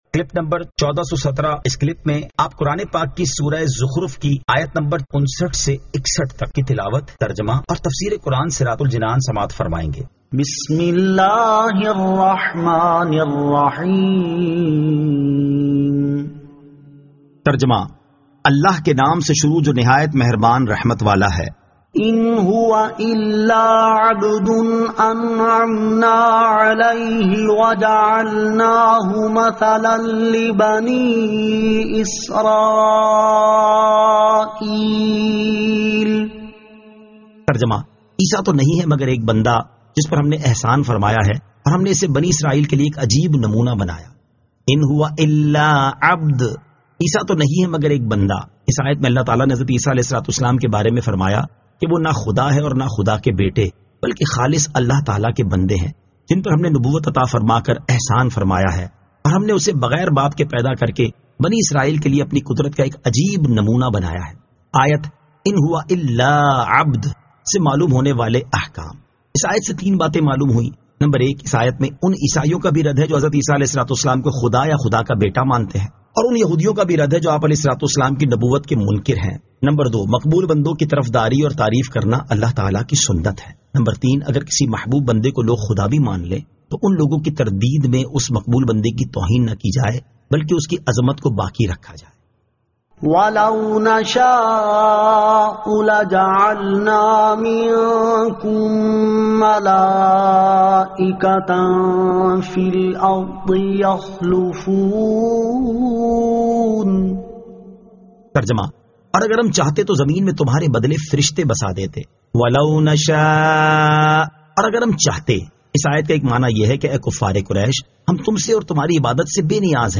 Surah Az-Zukhruf 59 To 61 Tilawat , Tarjama , Tafseer